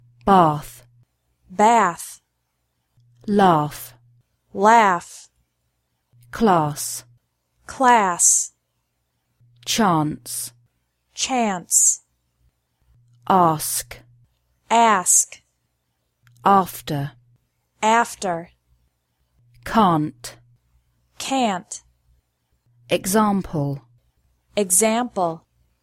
Listen to the difference – first in British English, then in American English
In some words, the letter “A” is pronounced differently in British and American English